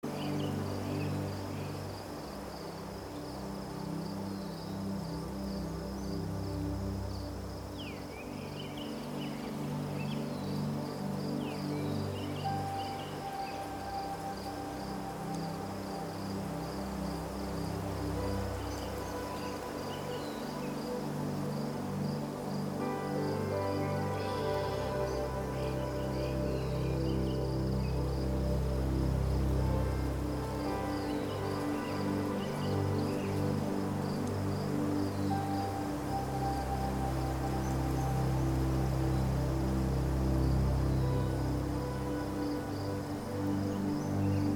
AmLife’s Original Frequency + Nature’s Frequency + Spiritual Healing Music
森林沐浴 FOREST BATHING PEACE 417Hz
放松纾压 Relaxation
消除焦虑 Anxiety Relief
forest.mp3